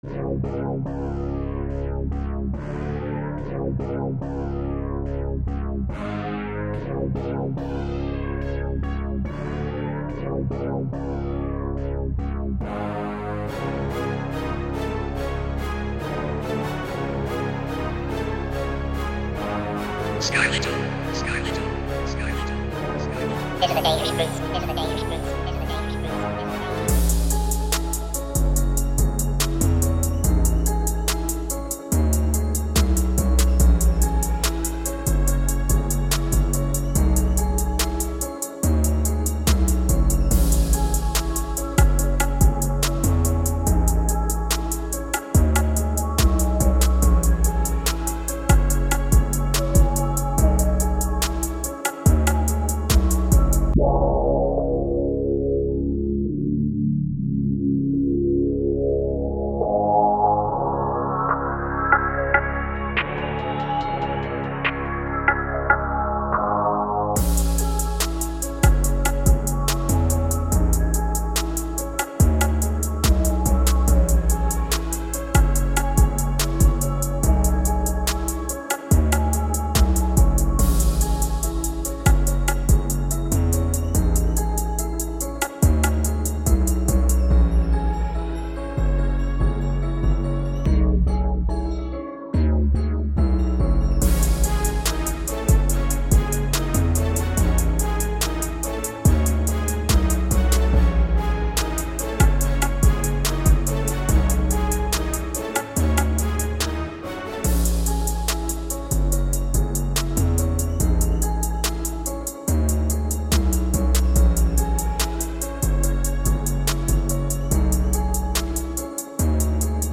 Instrumental Mixtape